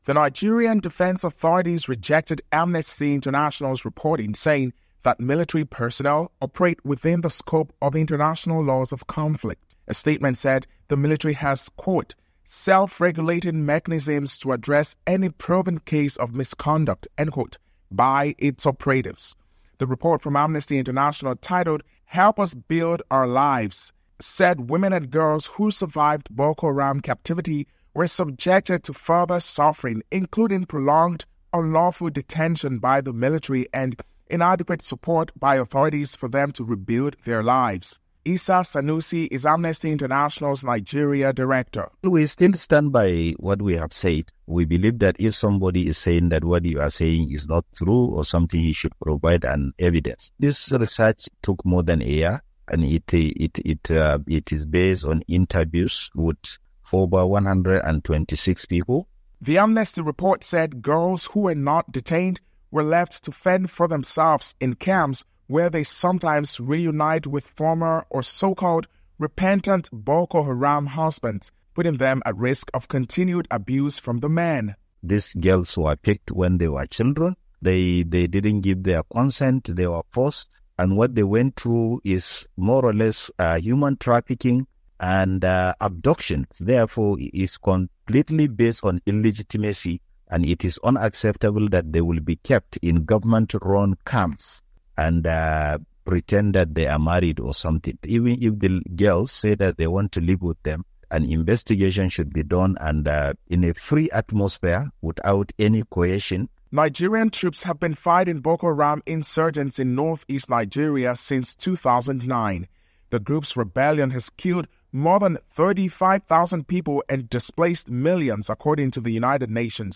reports from Abuja,